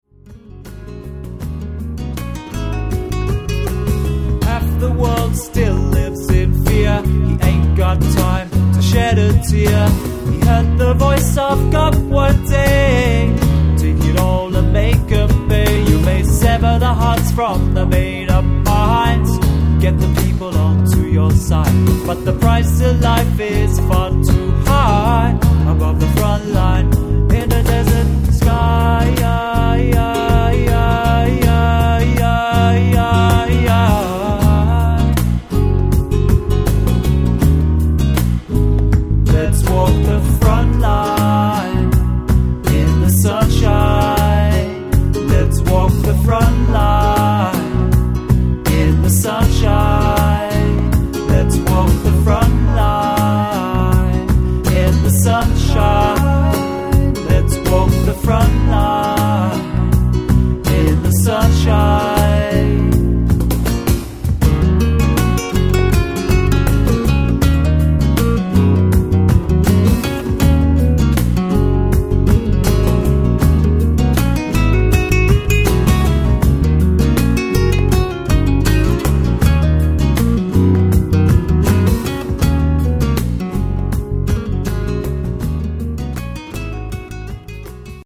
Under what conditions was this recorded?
This is a low quality section – 96kbs!